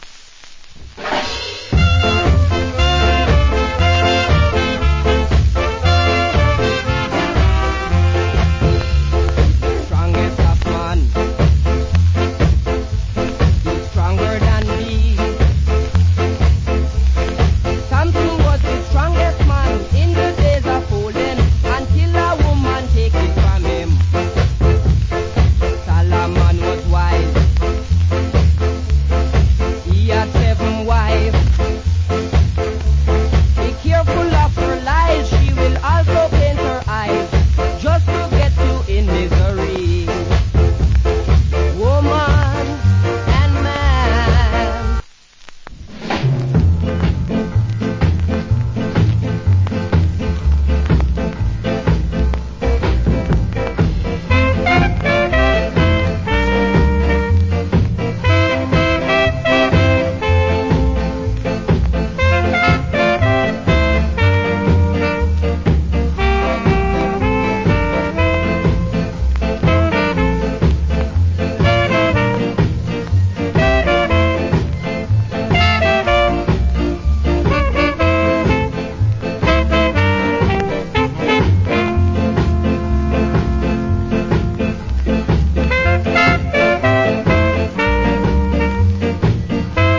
Condition VG(OK,HISS,LD) / VG(LD)
Wicked Ska Vocal.
/ Nice Ska Inst.